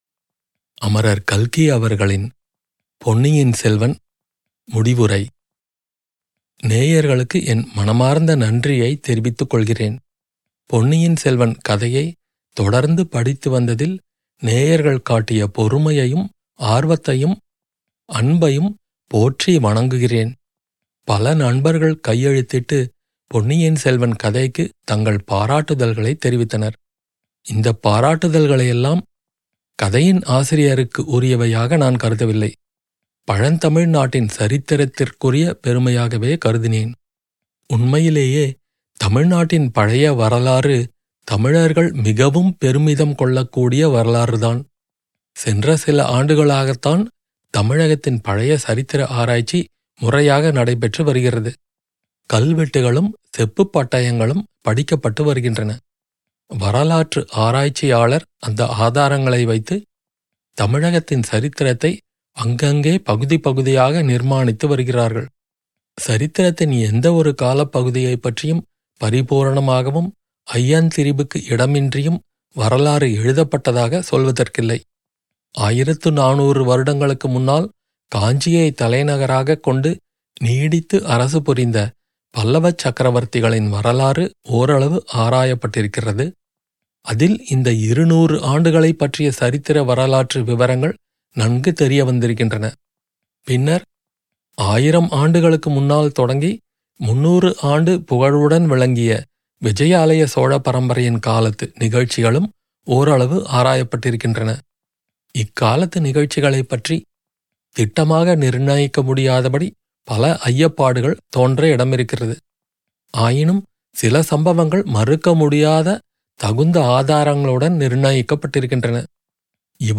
Ponniyin Selvan - Audio Book